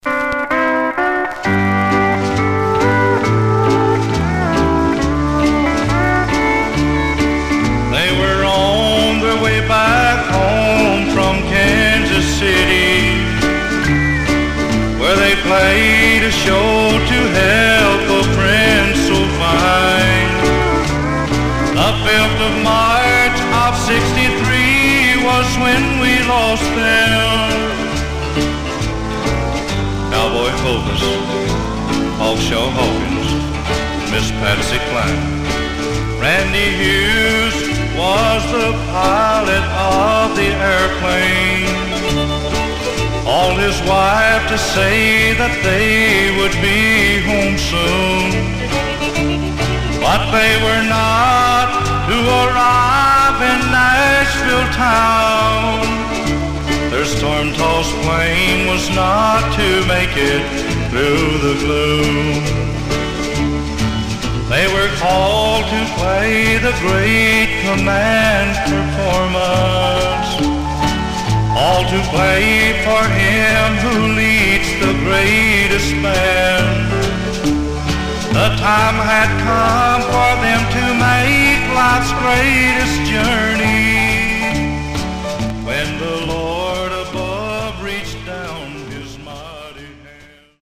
Mono
Country